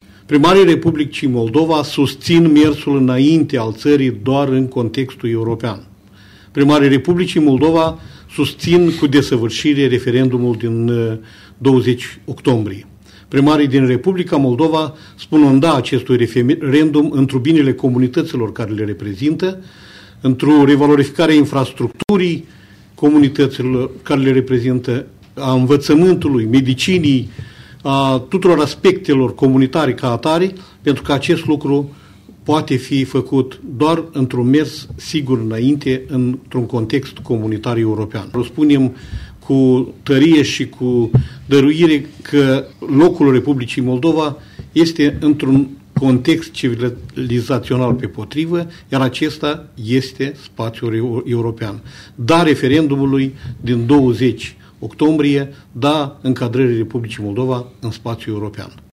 Primarii Republicii Moldova spun „Da!” acestui referendum întru binele comunităților pe care le reprezintă, întru revalorificarea infrastructurii localităților pe care le reprezintă, a învățământului, medicinei și a tuturor aspectelor comunitare, pentru că acest lucru poate fi făcut doar într-un mers sigur înainte într-un context european comunitar”, a declarat primarul municipiului Edineț, Constantin Cojocaru, într-o conferință de presă la IPN.